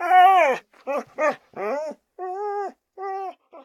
bdog_panic_0.ogg